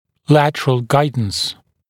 [‘lætərəl ‘gaɪdns][‘лэтэрэл ‘гайднс]боковое ведение